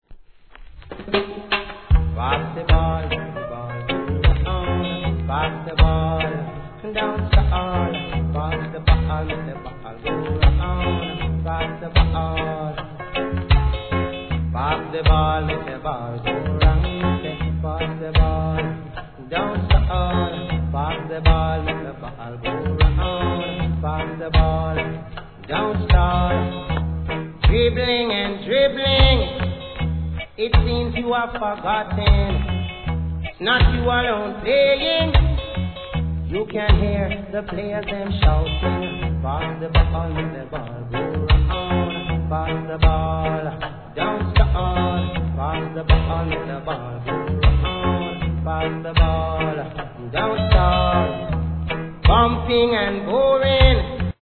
REGGAE
'80sの素晴らしいユル乗りROOTS!!